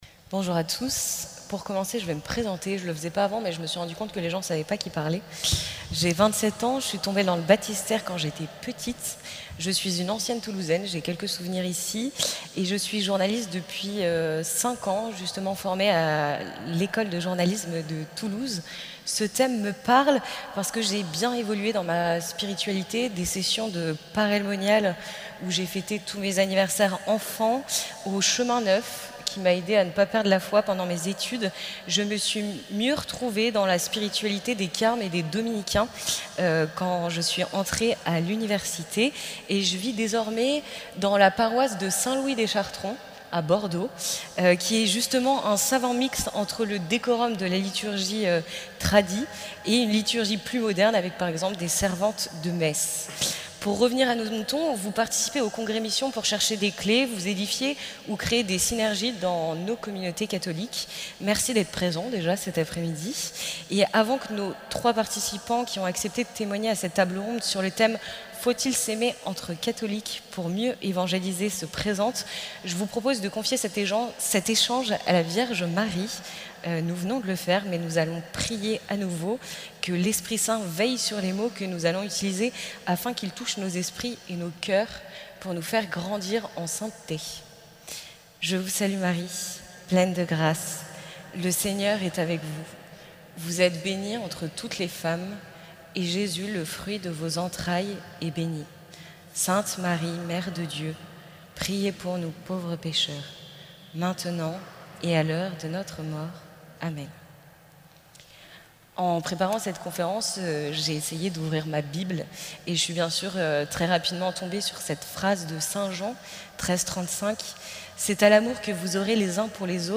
Faut-il être unis pour évangéliser, ou évangéliser chacun à sa manière ? Cette table ronde nous amènera à revenir sur ce qui nous unit, annoncer l’Evangile. Elle nous donnera des pistes pour qu’au-delà des divisions apparentes nous parvenions à susciter un élan missionnaire commun.